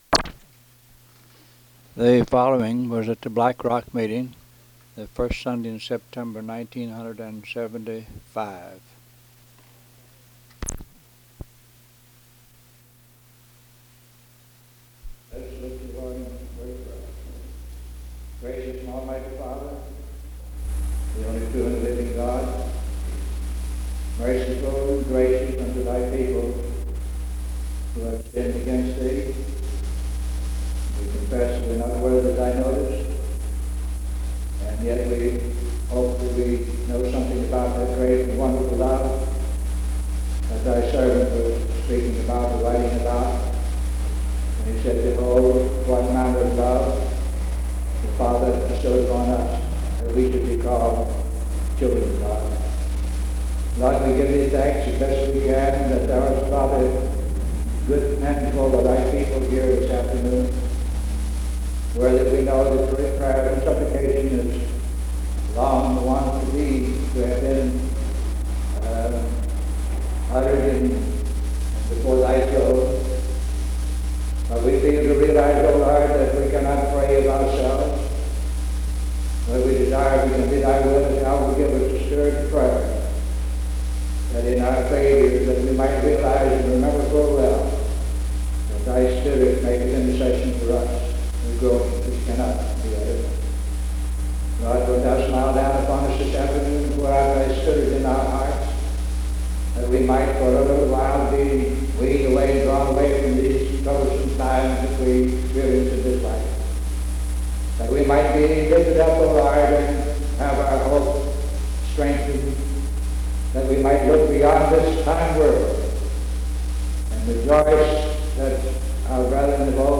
Sermons
Location Butler (Md.) Baltimore County (Md.)